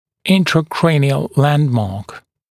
[ˌɪntrə’kreɪnɪəl ‘lændmɑːk][ˌинтрэ’крэйниэл ‘лэндма:к]внутричерепная (цефалометрическая) точка